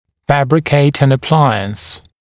[‘fæbrɪkeɪt ən ə’plaɪəns][‘фэбрикейт эн э’плайэнс]изготовить аппарат